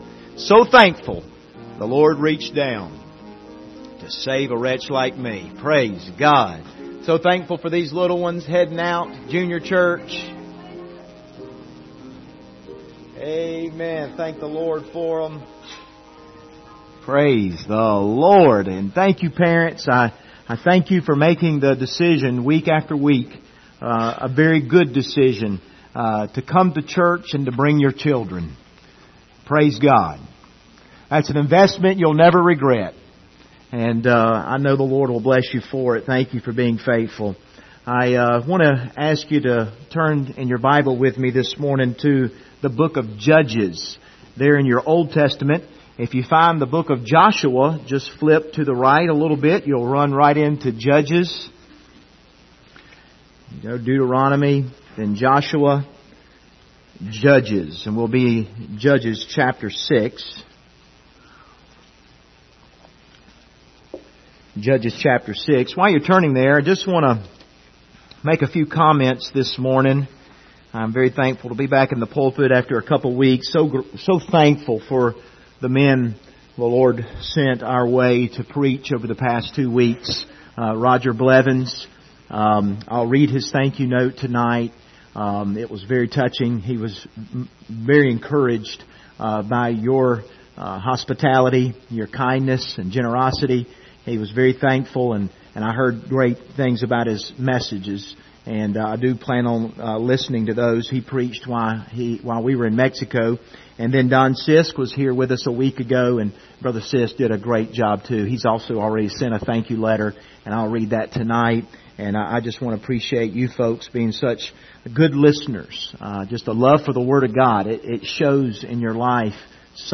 Judges 6 Service Type: Sunday Morning Topics